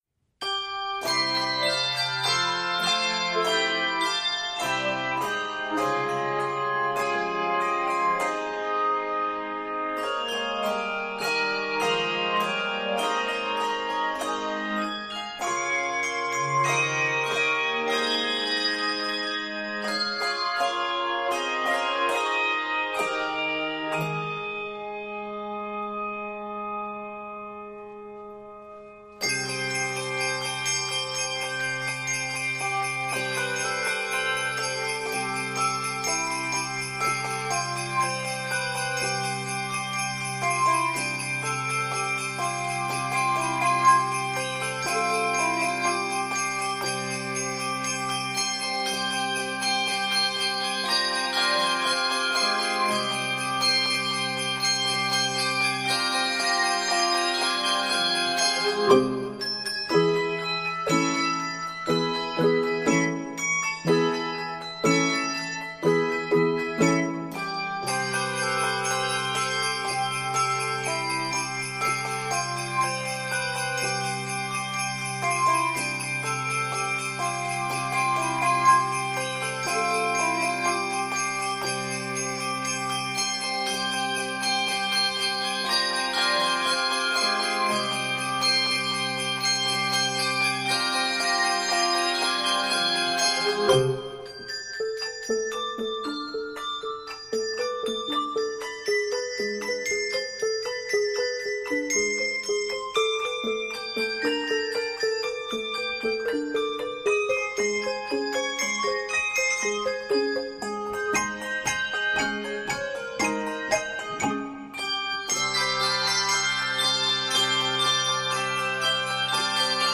for a lively, exciting holiday offering
for three, four, or five octaves with optional sleigh bells
Keys of Ab Major and C Major.